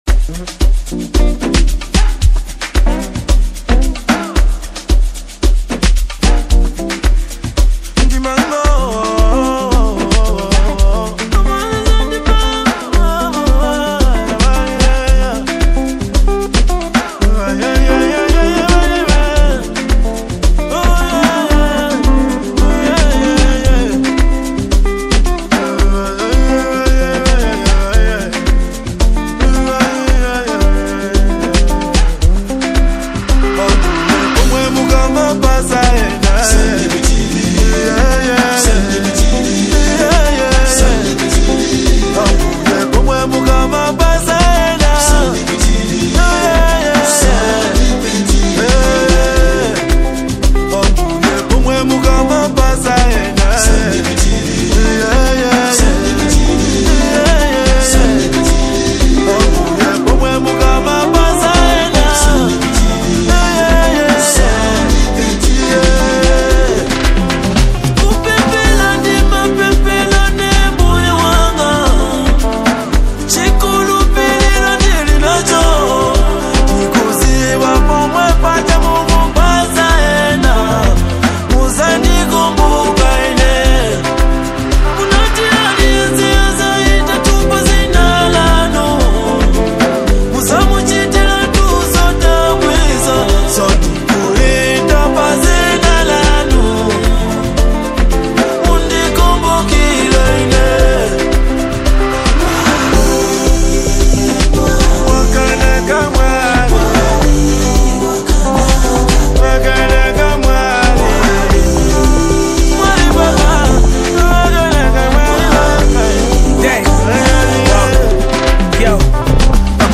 delivers a powerful blend of rhythm and emotion